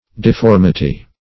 Search Result for " difformity" : The Collaborative International Dictionary of English v.0.48: Difformity \Dif*form"i*ty\, n. [Cf. F. difformit['e].